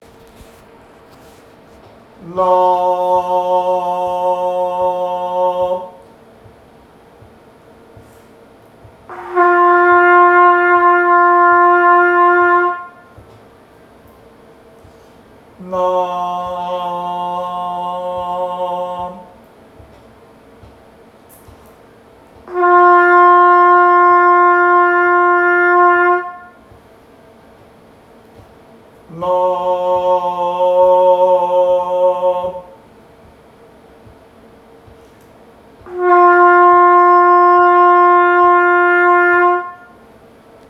お恥ずかしながら私がロングトーンの練習で最初に行う練習の実際の音です。
声で発生した後に楽器で演奏を繰り返すことで、楽器に自然な息が送れるようにしていく練習です。
【発生と交互に演奏することで息の流れを意識したロングトーン】
ロングトーンと声.wav